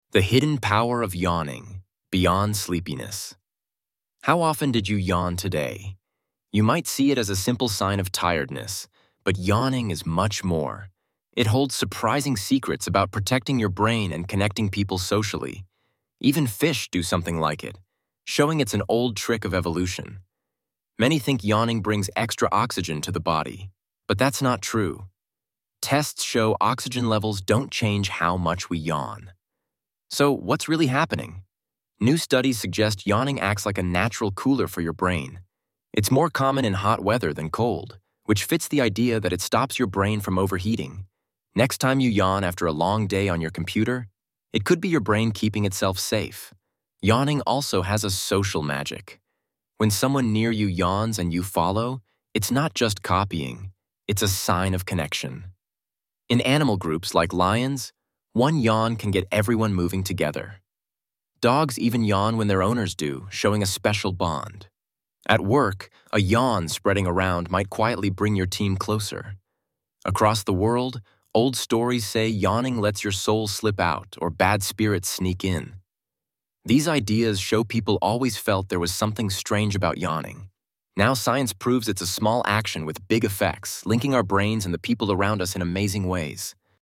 音声つき英語要約＆日本語訳